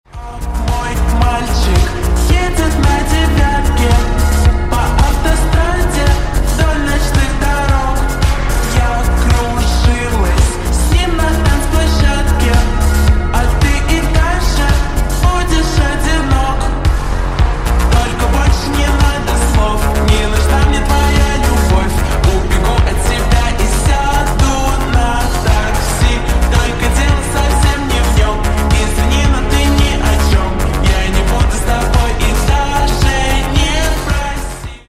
Рингтоны Ремиксы » # Поп Рингтоны